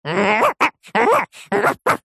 Звук веселого лая мультяшной собаки